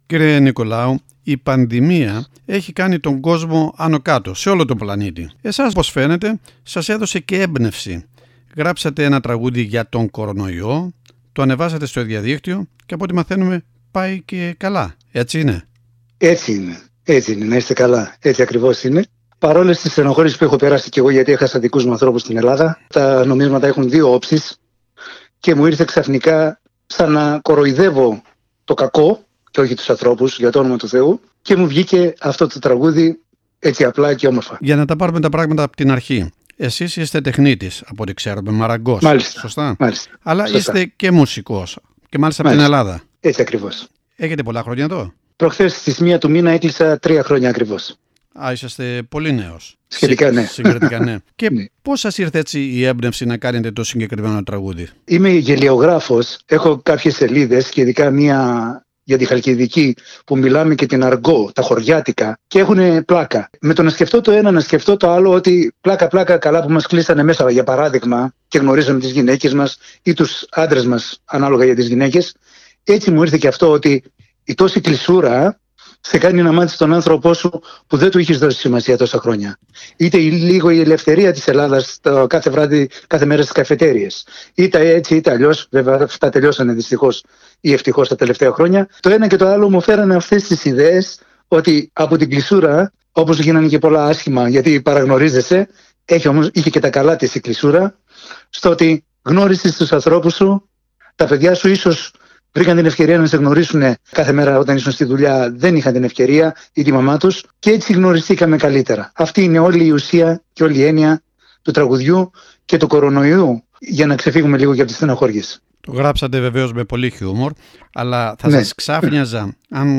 Το ρεφραίν, που τραγουδάει και acapella στη διάρκεια της συνέντευξης έχει ως εξής, και είναι αφιερωμένο στην ή στον σύντροφο με τους οποίους ένας ιός έμελλε να μας φέρει πιο κοντά λόγω lockdown.